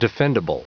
Prononciation du mot defendable en anglais (fichier audio)
defendable.wav